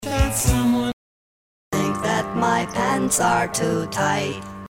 "dirty," in other words, with a burst of distortion often accompanying
For a quick MP3 clip of two sibilance examples, please go to:
sdistort.mp3